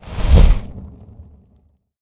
meleeattack-swoosh-magicaleffect-group01-fire-03.ogg